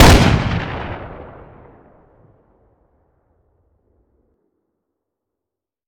weap_western_fire_plr_atmo_ext1_04.ogg